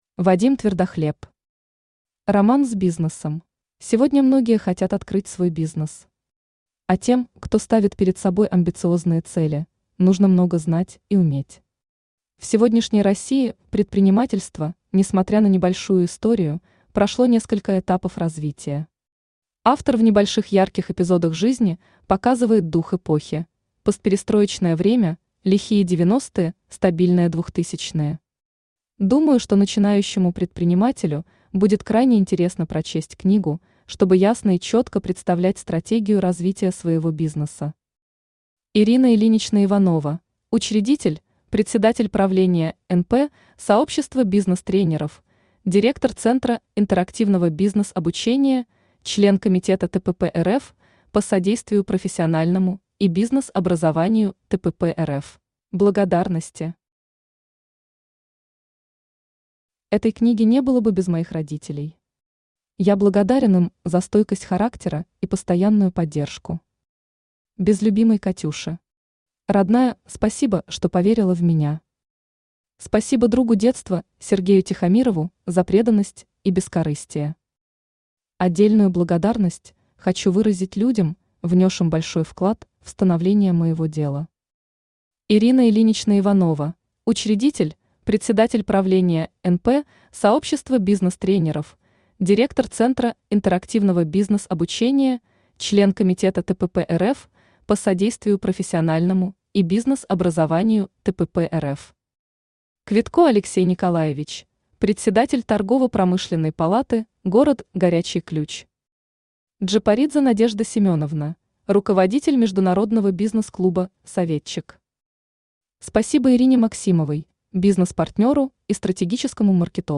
Аудиокнига Роман с бизнесом | Библиотека аудиокниг
Aудиокнига Роман с бизнесом Автор Вадим Твердохлеб Читает аудиокнигу Авточтец ЛитРес.